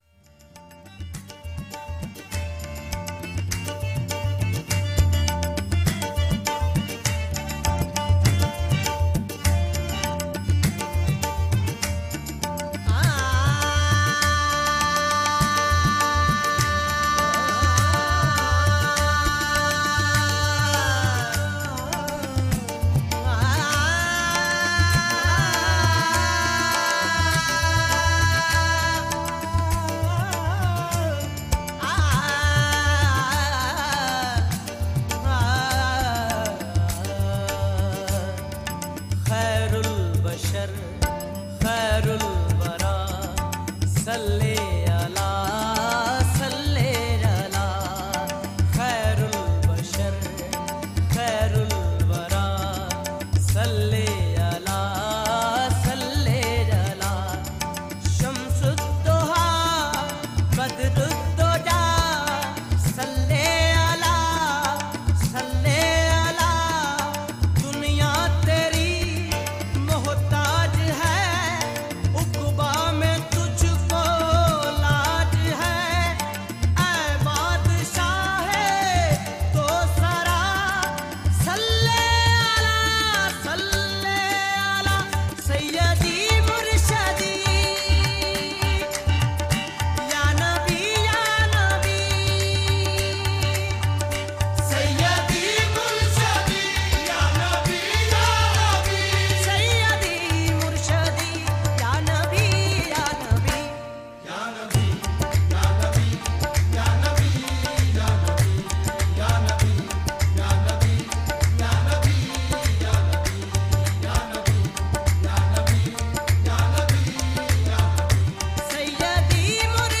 naat
performed in a traditional qawwali style and soulful voice